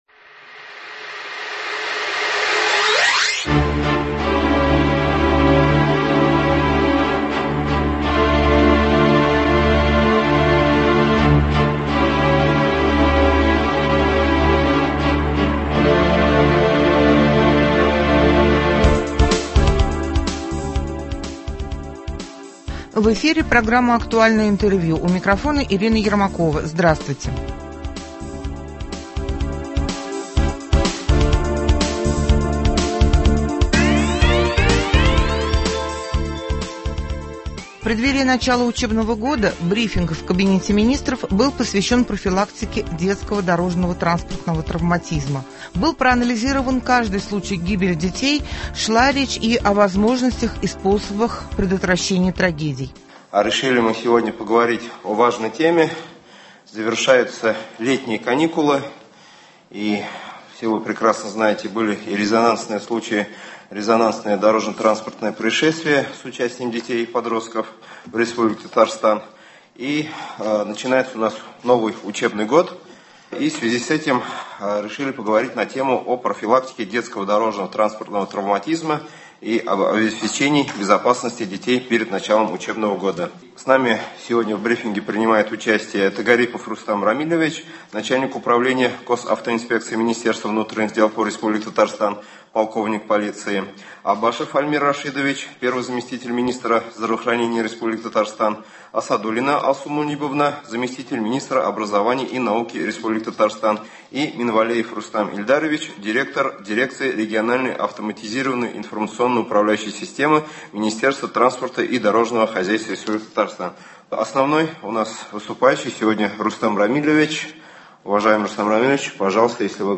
В Доме Правительства РТ прошел брифинг по профилактике детского дорожно-транспортного травматизма.
Актуальное интервью (28.08.24)